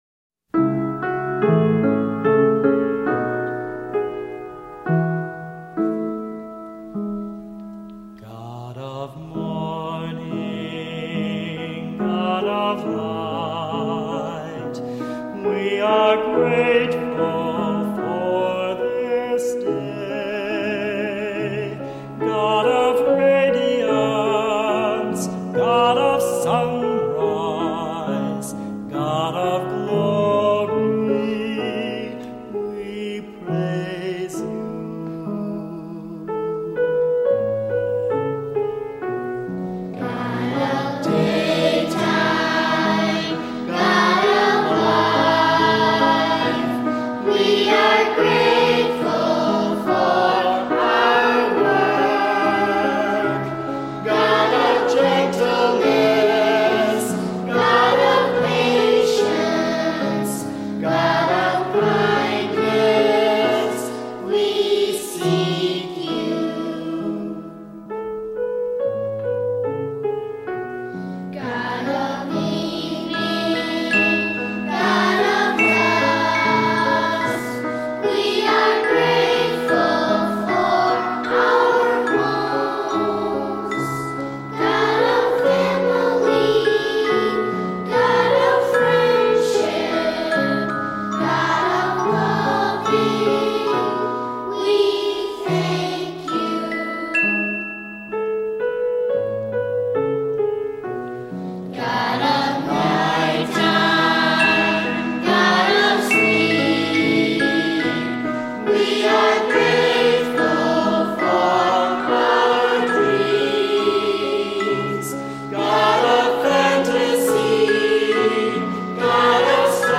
Voicing: Unison Choir